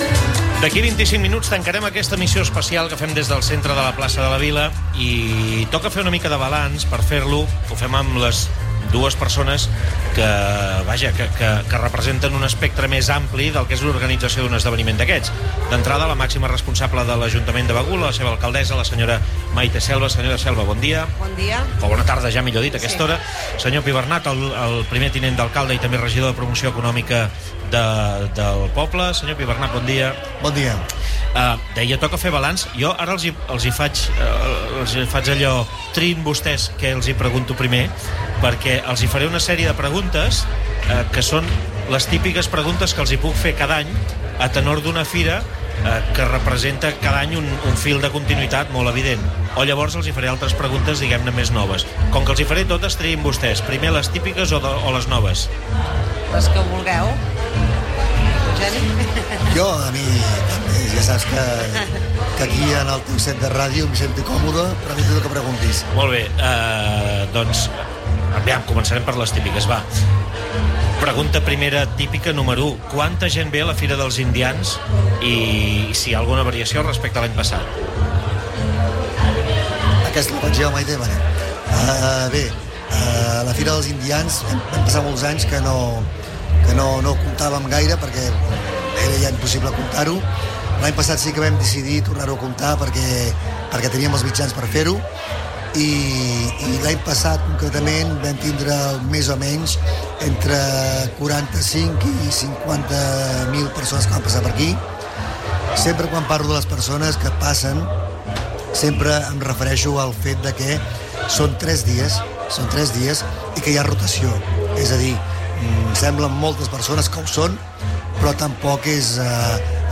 Ràdio Capital de l’Empordà va tancar el diumenge la seva retransmissió de la Fira d’Indians amb una entrevista a l’alcaldessa de Begur, Maite Selva, i el regidor de Promoció Econòmica, Eugeni Pibernat.
Entrevista_Selva_Pibernat.mp3